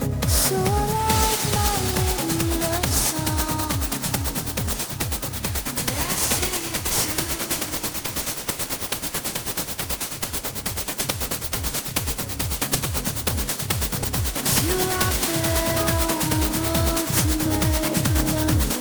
They are virtually identical : if you invert one and play them in sync with each other they cancel out when the amplitude matches … /uploads/default/original/2X/c/cc3e7a987ed7b875976d82fd4895db61ad1c49ee.mp3 Different version of LAME encoder used on each, and slightly different equalization …